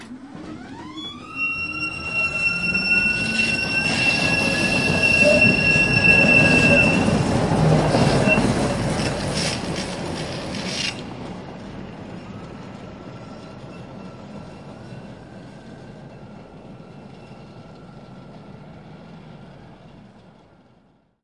里加声音文件 " 里加拉脱维亚。乘坐有轨电车铁路公共运输
描述：在里加拉脱维亚乘坐1号有轨电车铁路2008
Tag: 公共 电车 transportrailway 拉脱维亚 里加